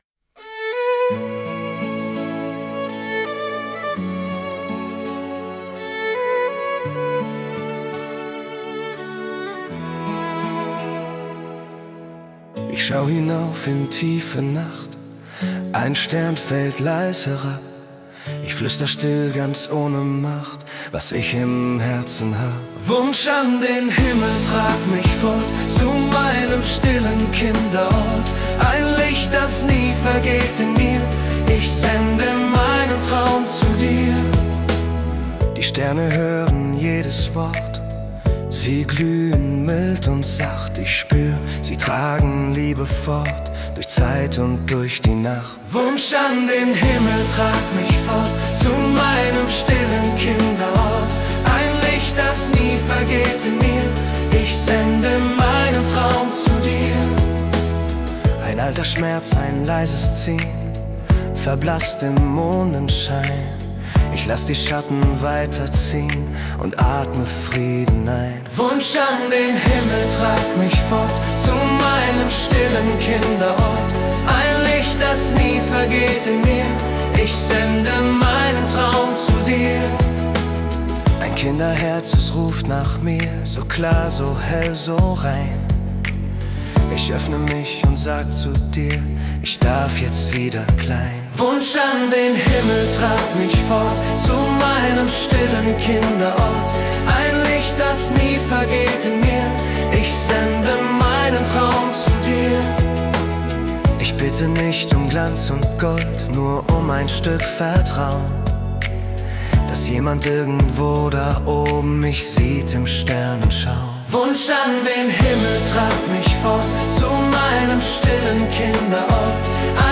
→ Das leise Gebet des inneren Kindes.